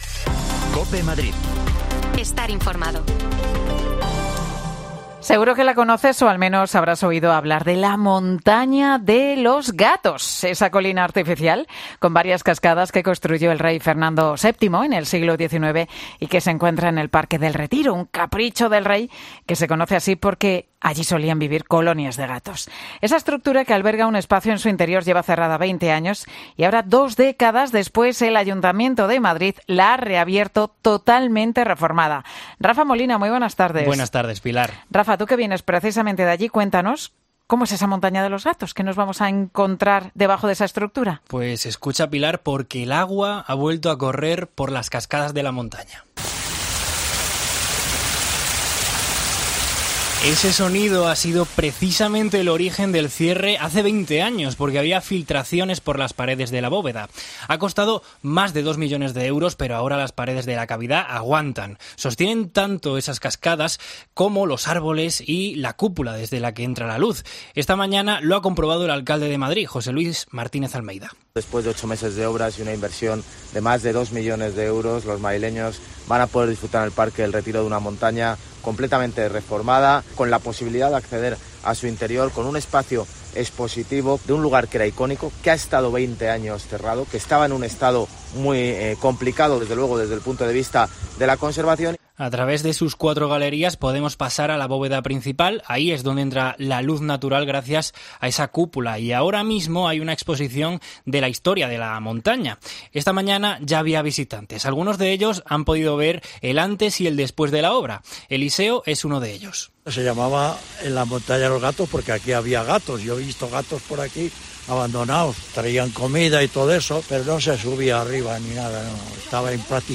Sostienen tanto esas cascadas que escuchabas como los árboles y la cúpula desde la que entra la luz.